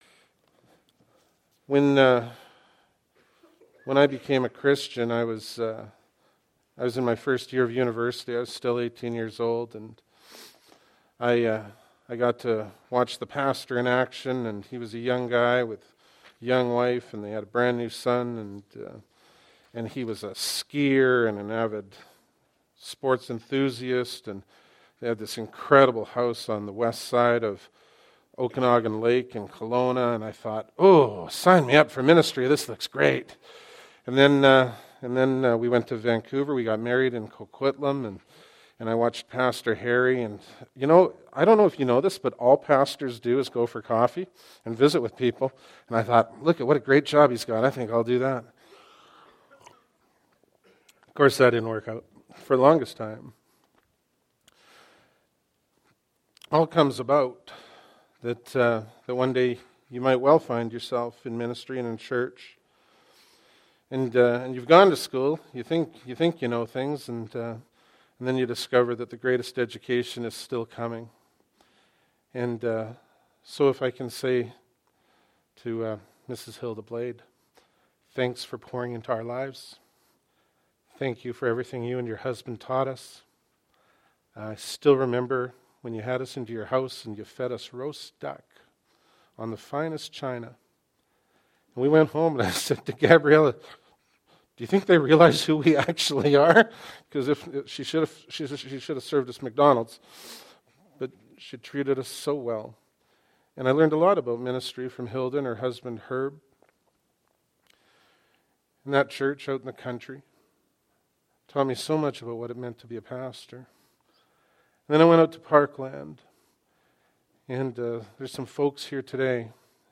Bible Text: Matthew 21:1-11 | Preacher